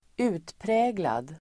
Uttal: [²'u:tprä:glad]